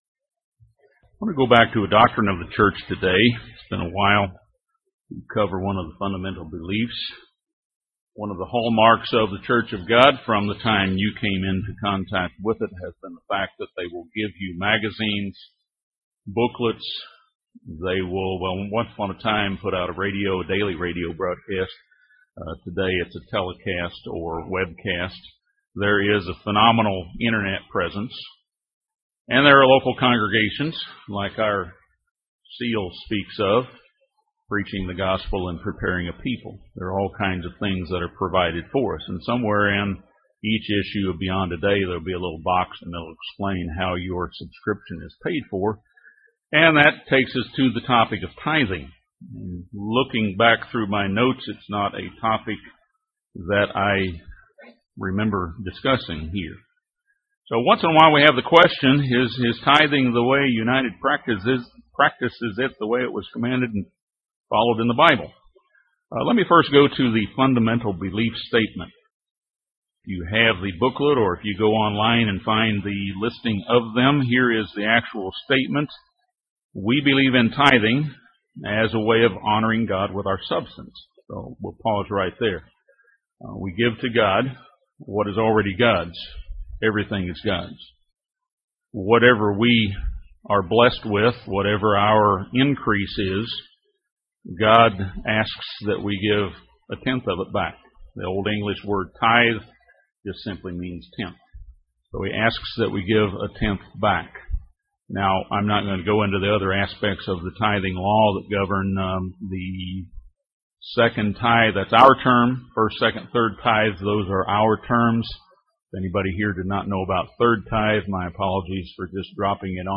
This sermon discusses many of the scriptures that governing tithing and how it has been administered across the centuries.
Given in Gadsden, AL Huntsville, AL